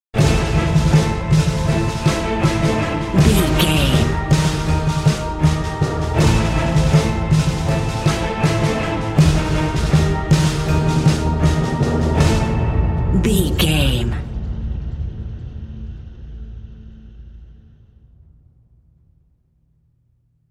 Lydian
strings
percussion
synthesiser
ominous
dark
suspense
haunting
tense
creepy